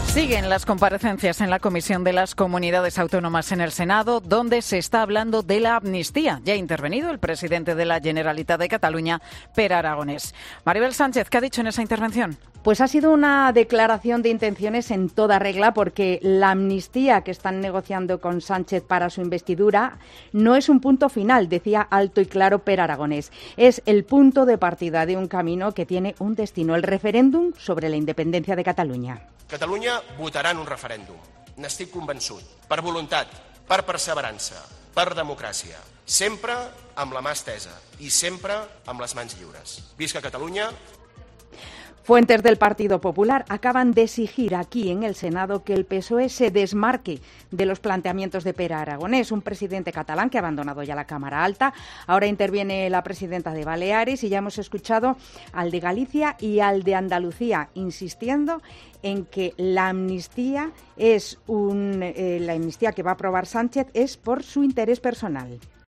Así de contundente ha defendido el presidente de la Generalitat, Pere Aragonès, la amnistía en su comparecencia en el Senado ante los barones del Partido Popular durante su intervención ante la Comisión General de Comunidades Autónomas.